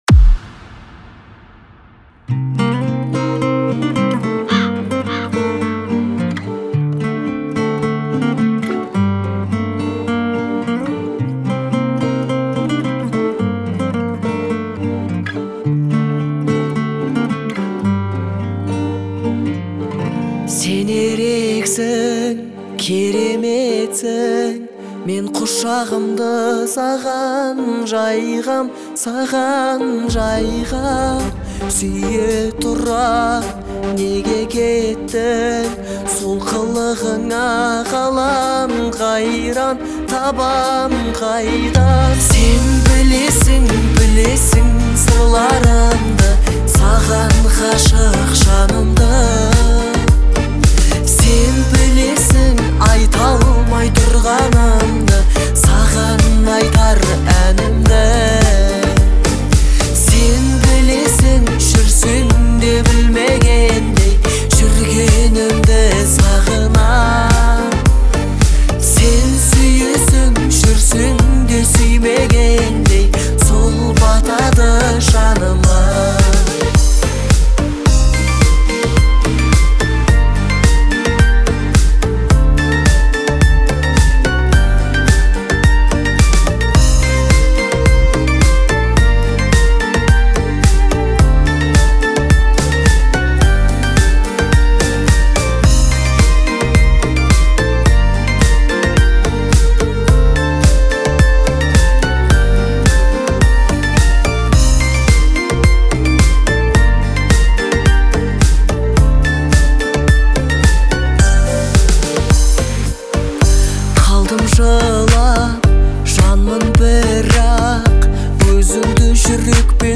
это трек в жанре казахской поп-музыки